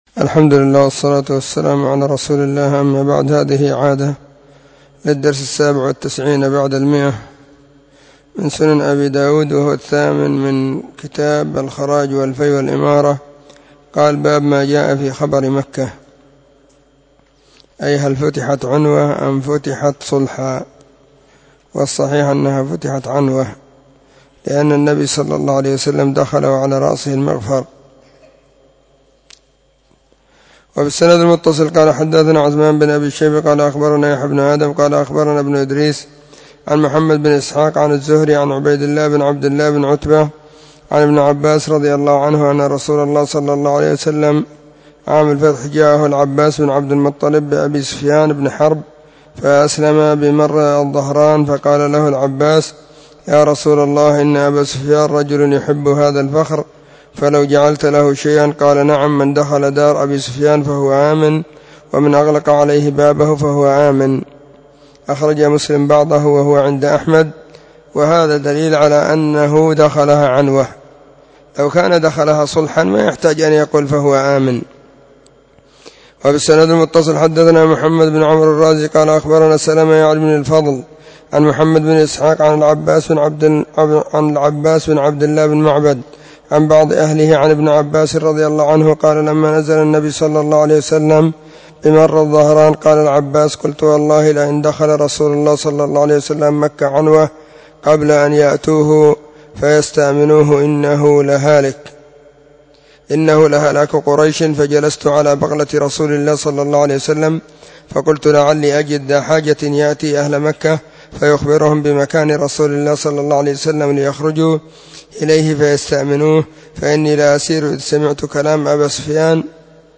🕐 [بعد صلاة العصر في كل يوم الجمعة والسبت]
📢 مسجد الصحابة بالغيضة, المهرة، اليمن حرسها الله.